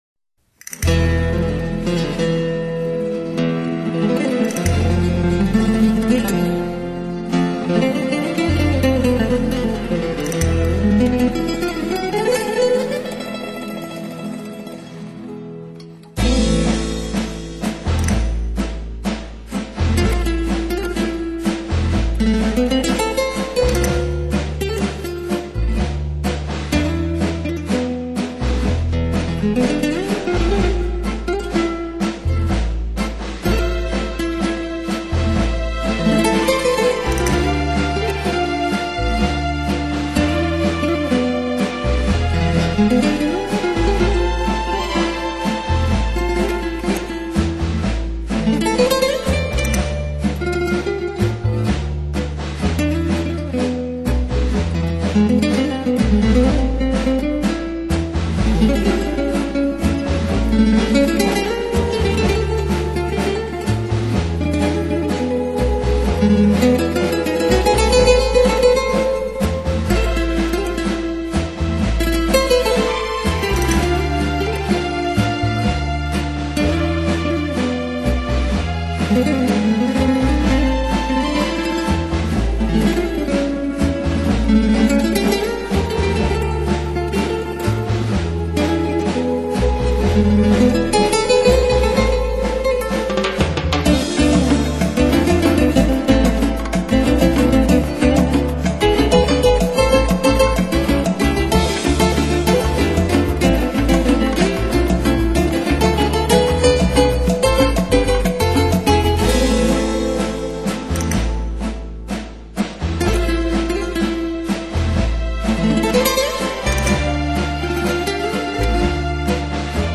Tango(탱고)